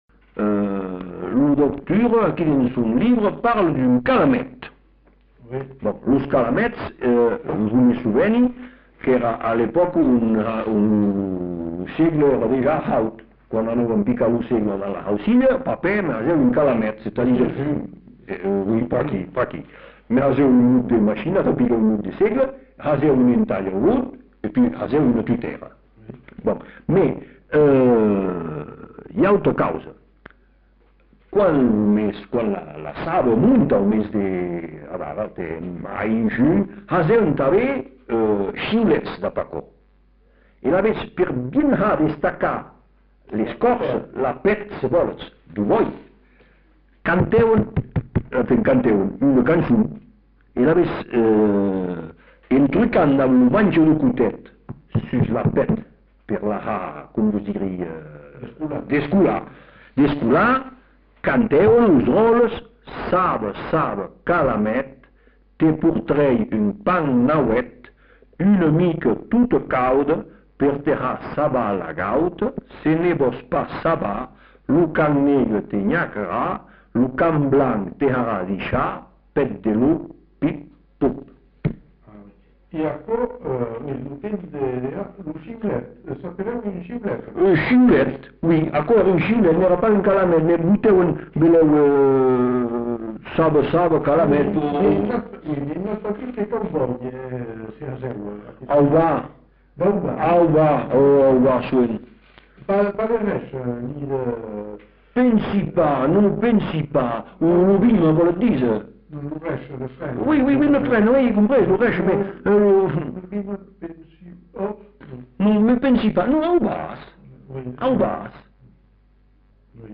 Lieu : Bazas
Genre : témoignage thématique/forme brève
Instrument de musique : sifflet végétal
Classification : formulette enfantine